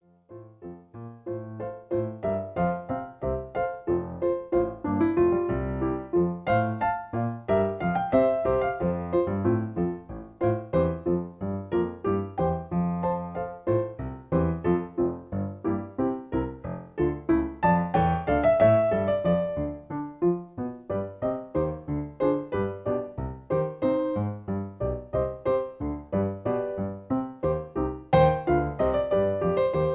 In B-flat. Piano Accompaniment